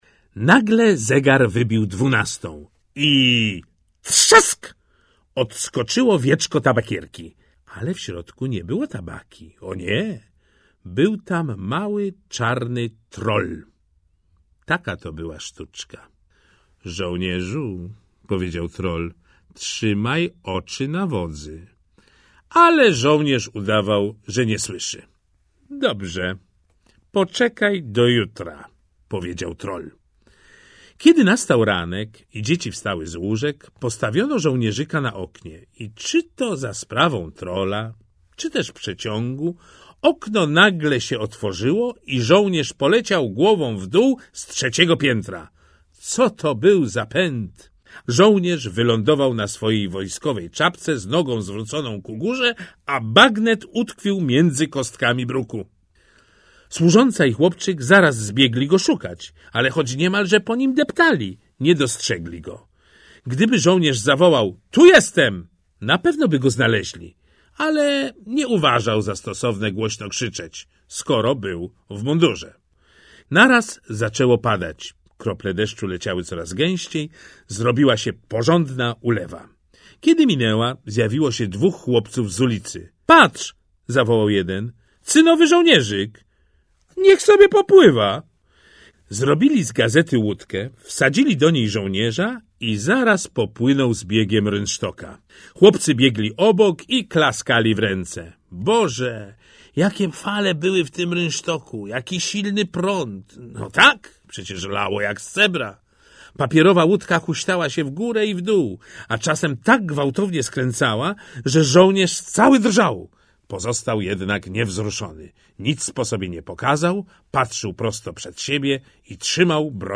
Baśnie 4 - Hans Christian Andersen - audiobook